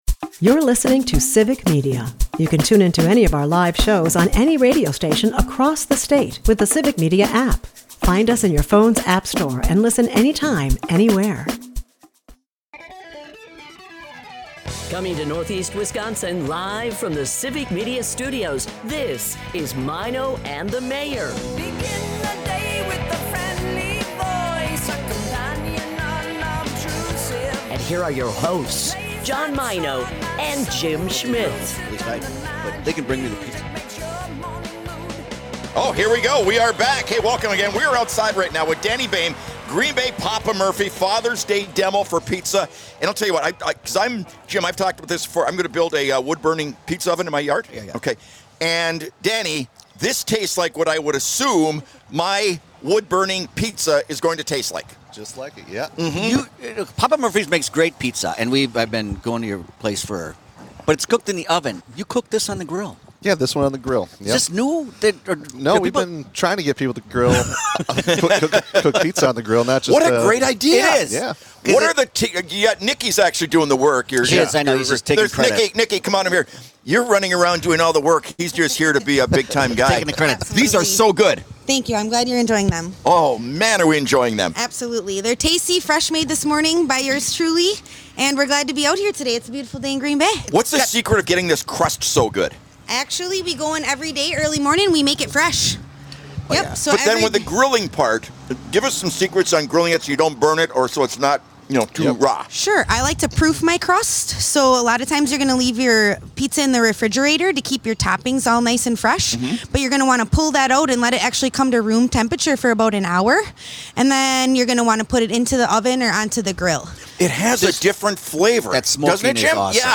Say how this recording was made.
which features a live music session